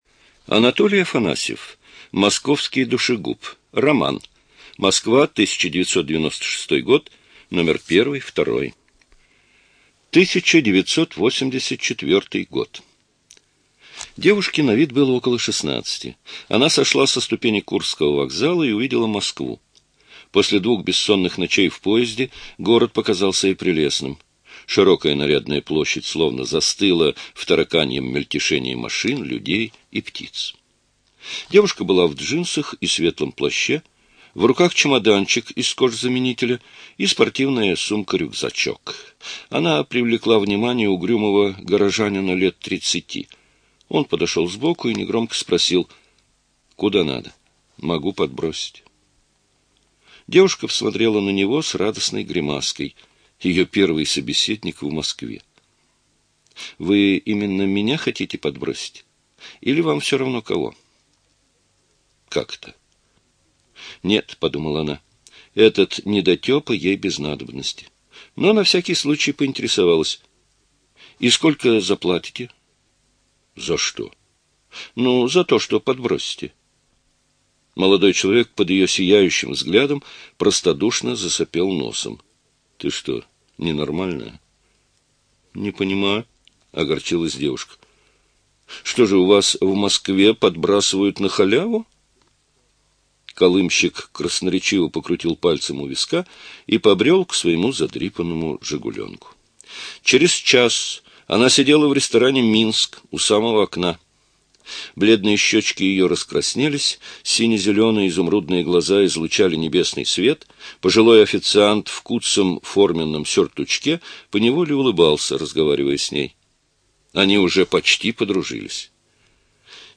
ЖанрДетективы и триллеры
Студия звукозаписиЛогосвос